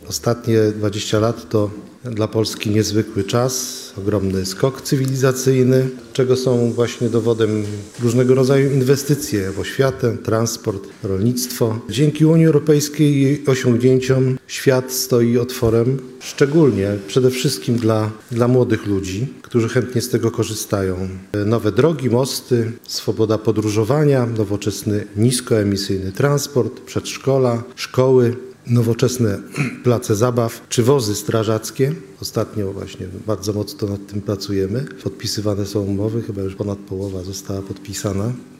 ,,Jak w tym czasie zmienił się region ostrołęcki i życie jego mieszkańców”? – to temat konferencji podsumowującej minione lata, która odbyła się w Ostrowi Mazowieckiej z przedstawicielami samorządów lokalnych i organizacji.
Dzięki funduszom europejskim Mazowsze nieustannie się rozwija – podkreślał wicemarszałek woj. mazowieckiego, Wiesław Raboszuk: